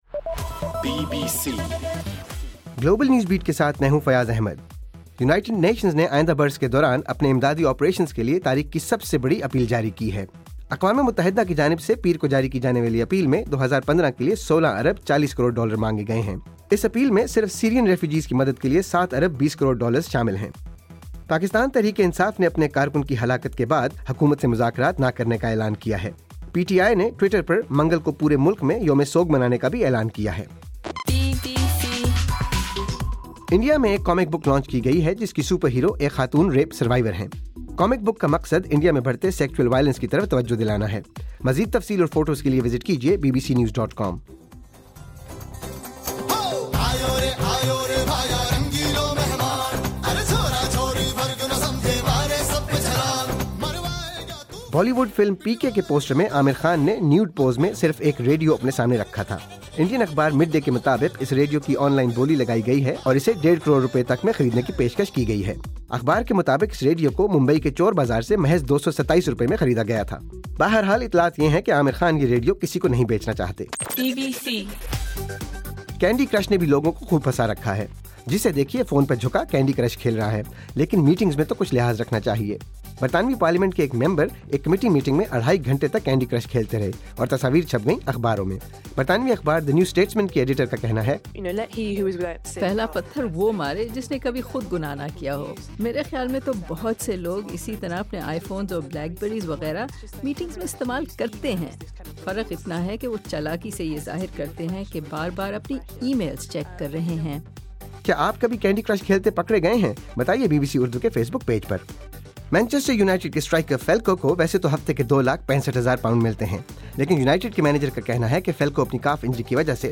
دسمبر 9: صبح 1 بجے کا گلوبل نیوز بیٹ بُلیٹن